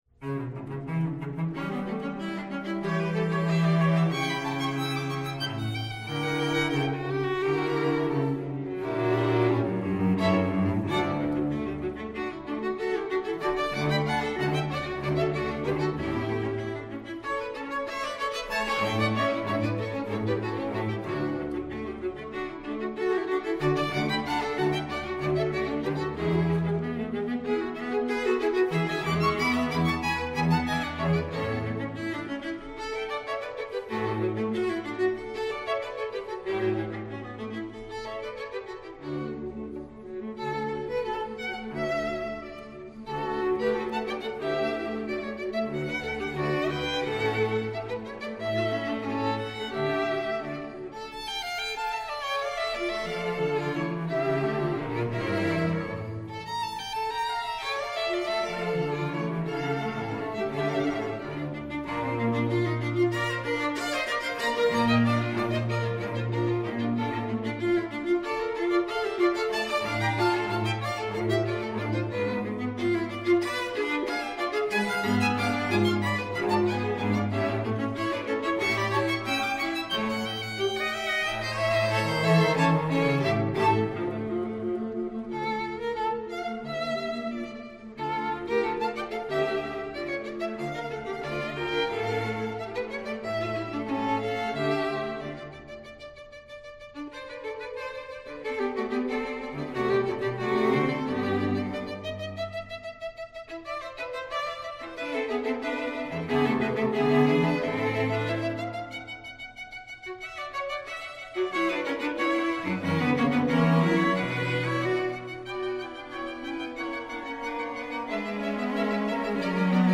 Soundbite 4th Movt
String Quartet in A Major